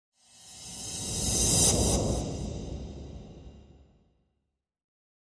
[add] Added a sound effect at ResultScene.